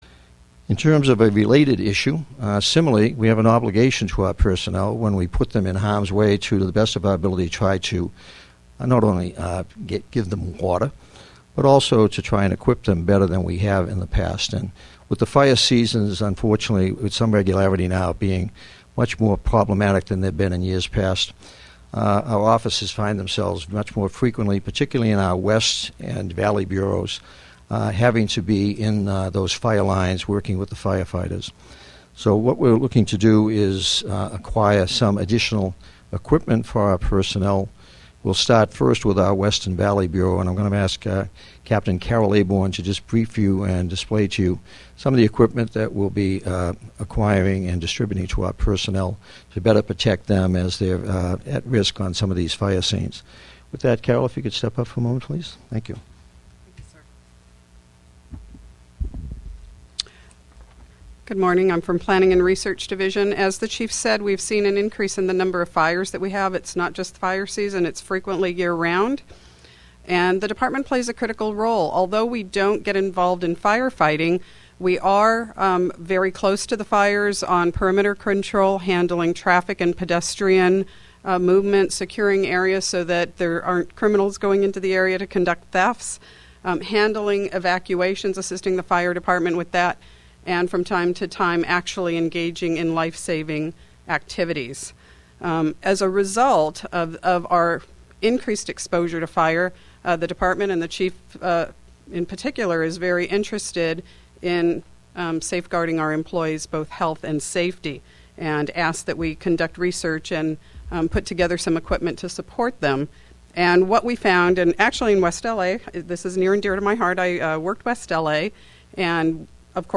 Chief Bratton Hosts Media Availability News Conference for March Topics Include Honors for Heroism and Chief’s Request for Federal Law Enforcement Funds